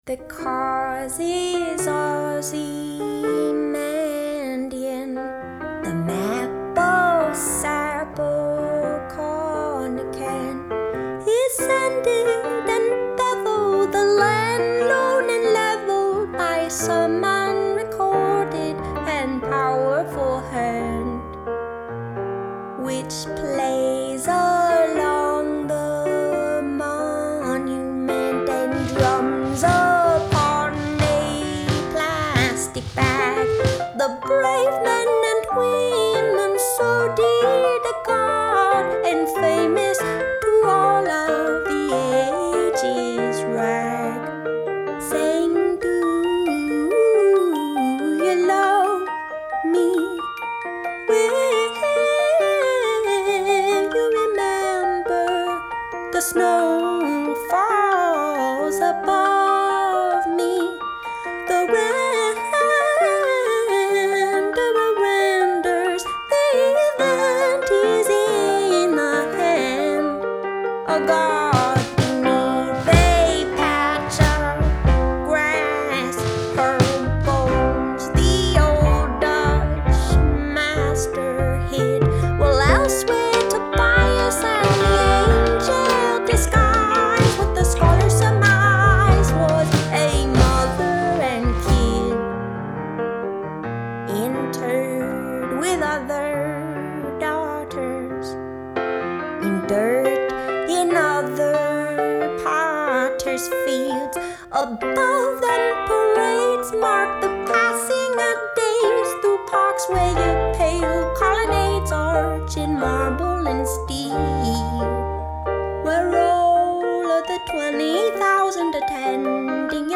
Genre: Indie Folk, Singer-Songwriter